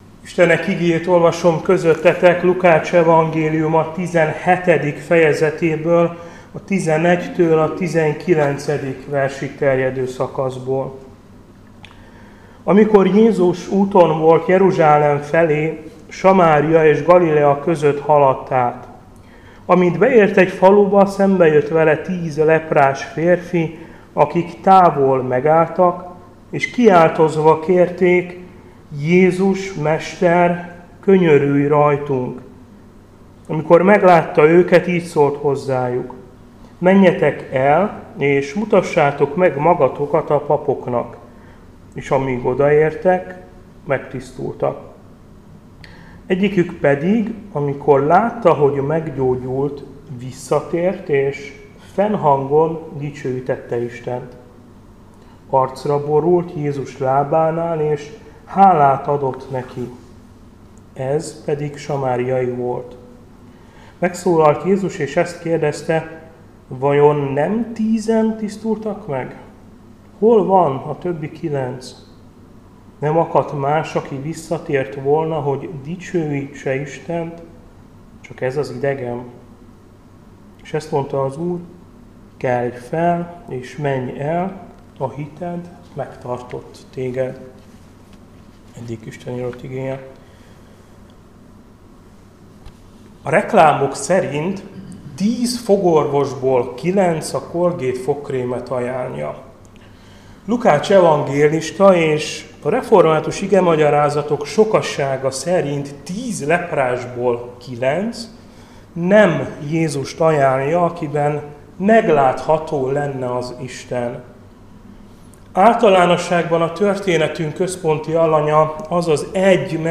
Áhítat, 2025. március 4.